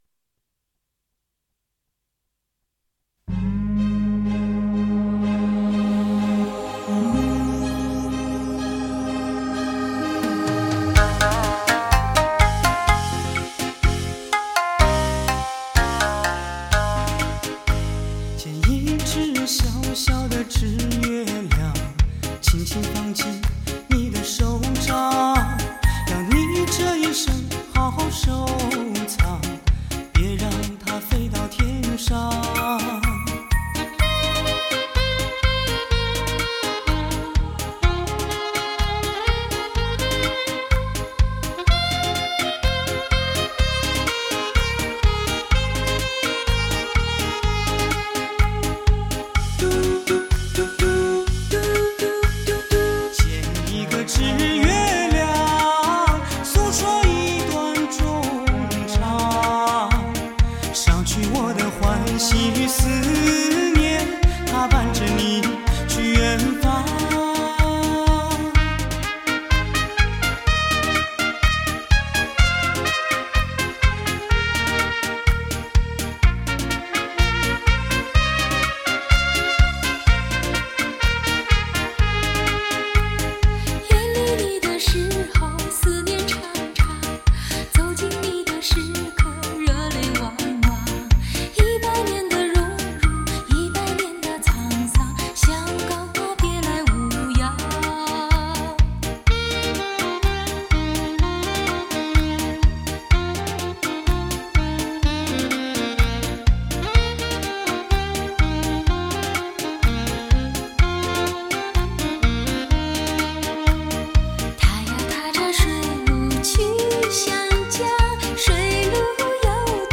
Song and dance
classics love song
（伦巴）